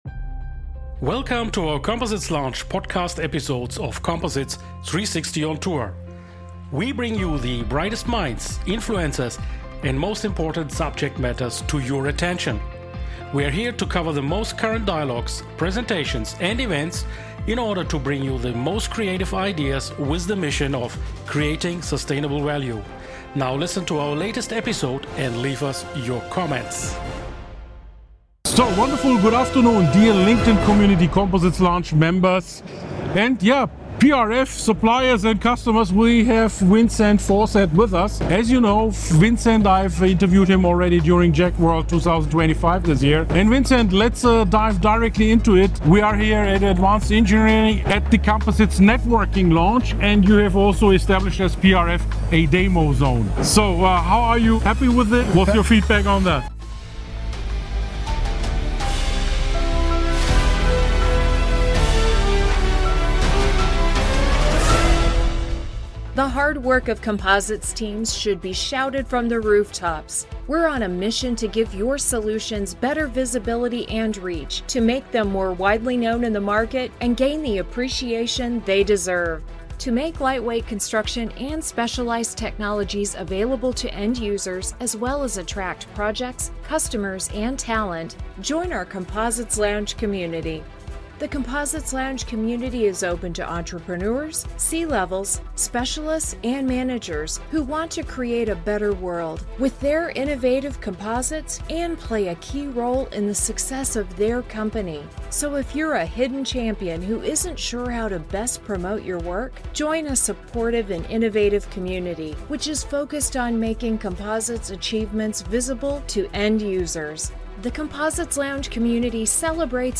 At the recent Composites Networking Lounge and Demo Zone during